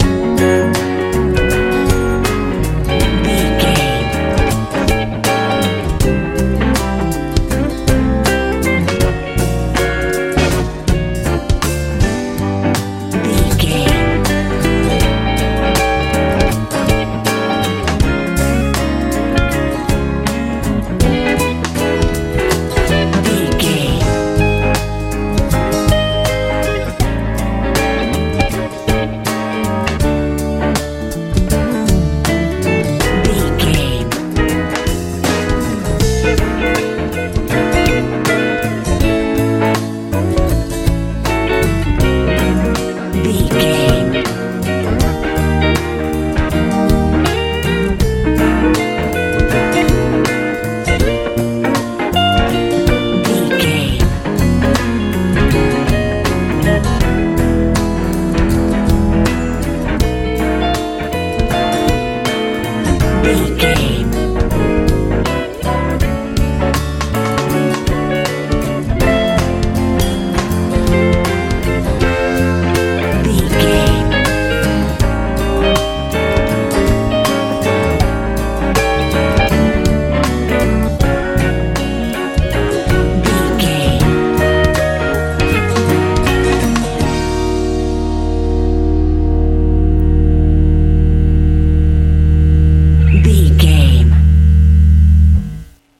lite pop feel
Ionian/Major
F♯
bright
electric guitar
bass guitar
drums
80s
soothing
soft